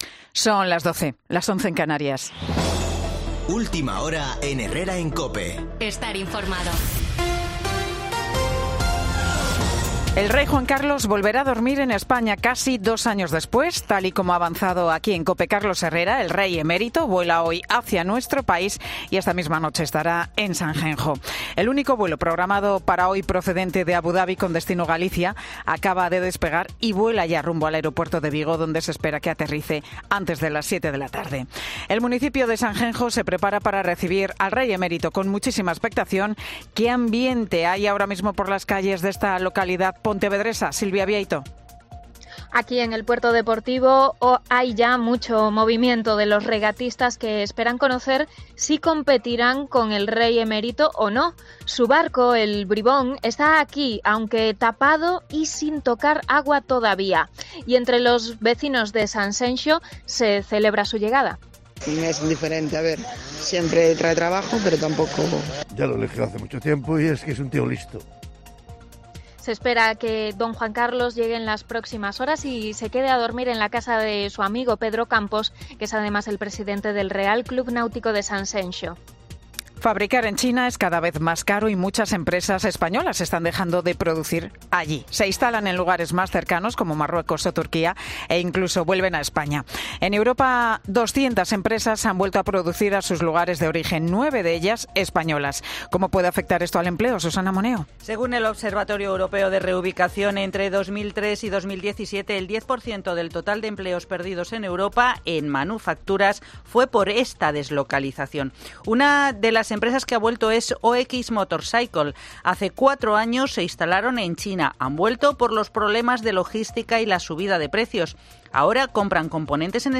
AUDIO: Boletín de noticias COPE del 19 de mayo de 2022 a las 12.00 horas